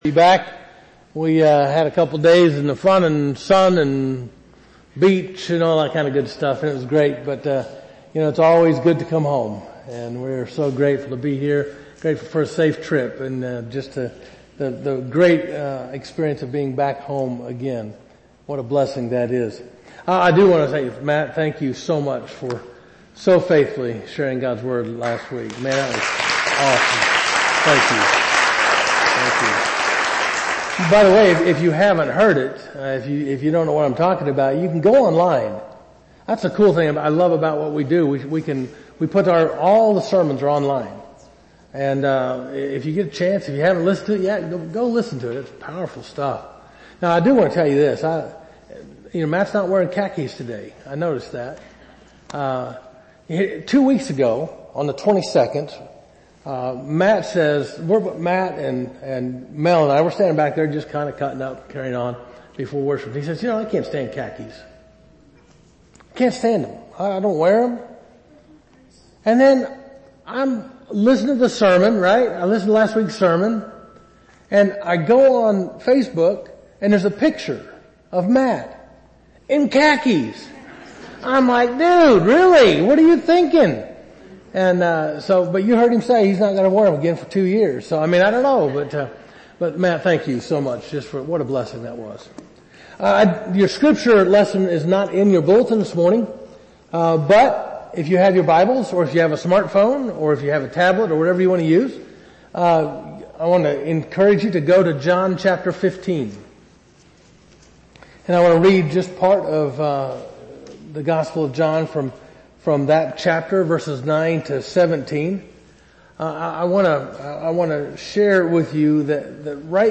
Covenant United Methodist Church Sermons